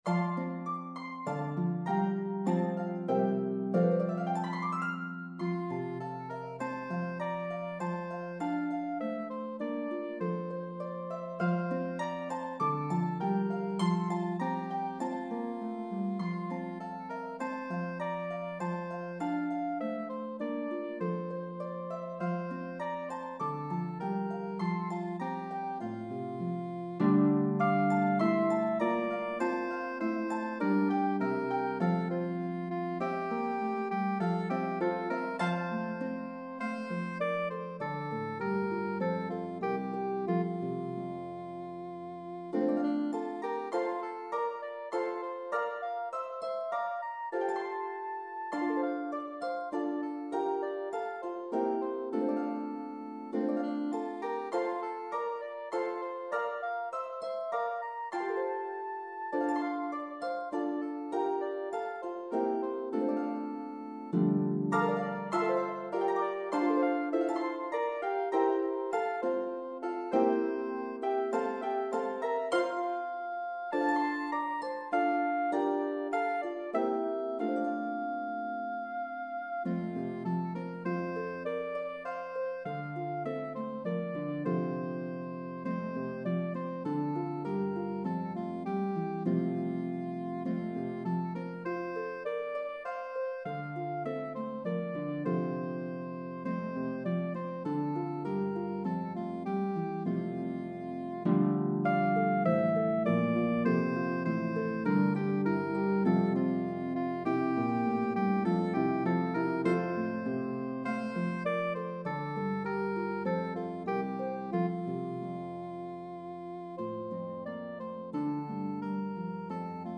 The exuberant French Carol